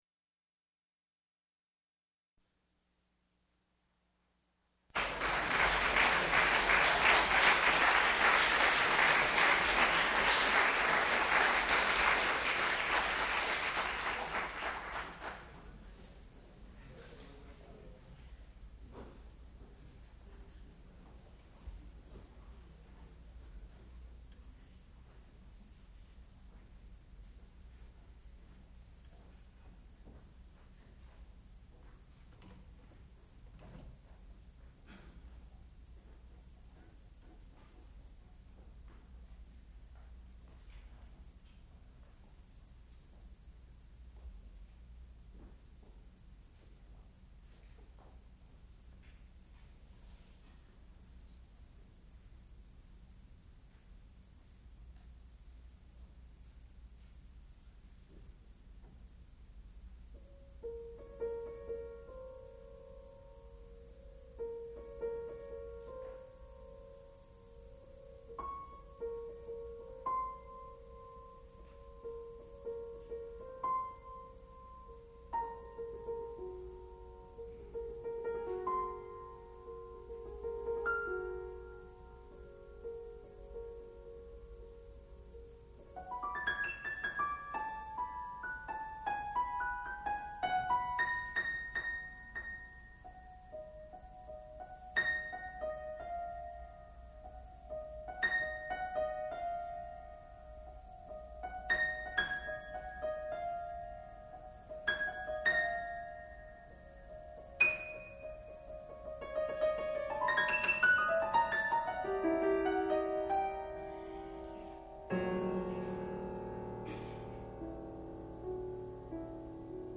sound recording-musical
classical music
Master's Recital
piano